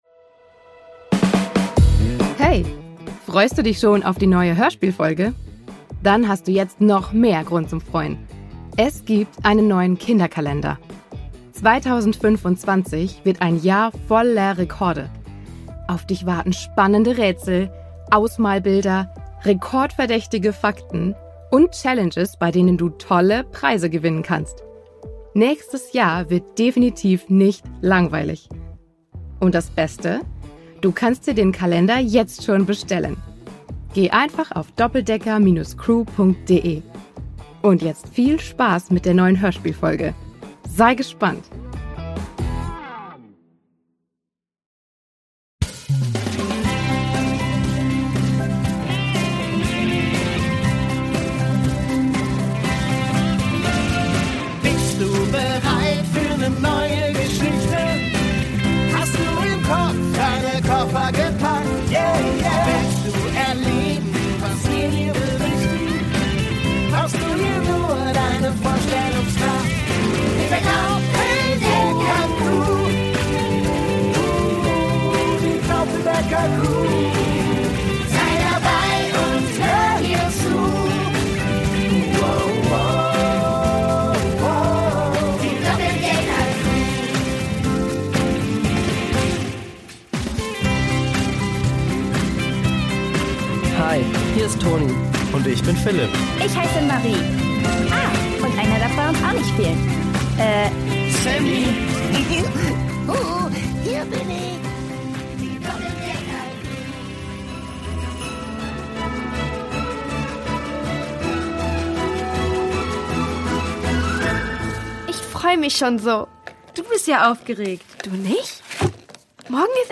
Weihnachten 1: Licht aus! | Die Doppeldecker Crew | Hörspiel für Kinder (Hörbuch) ~ Die Doppeldecker Crew | Hörspiel für Kinder (Hörbuch) Podcast